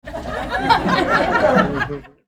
Concert Crowd